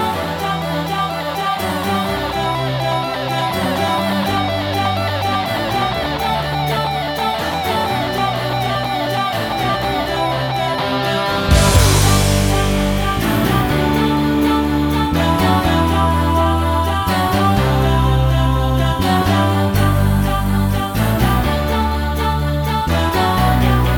No Backing Vocals Soundtracks 3:49 Buy £1.50